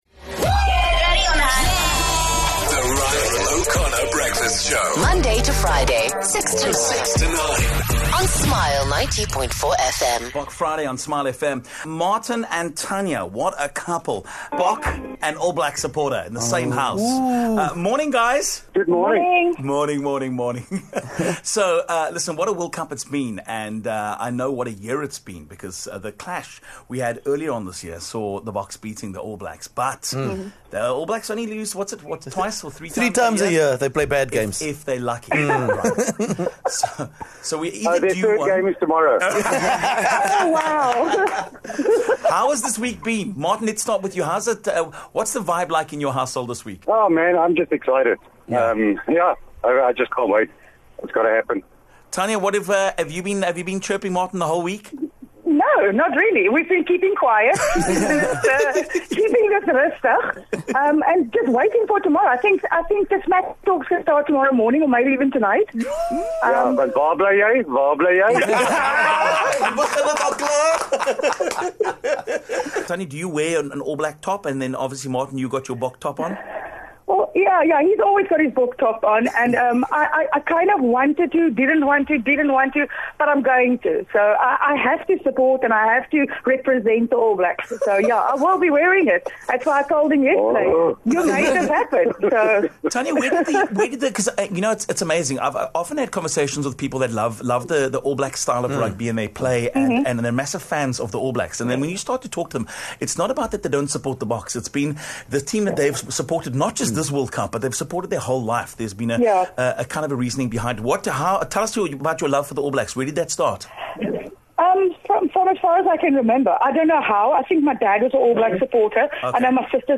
We also got the best South African version of a haka that we've heard in a long time.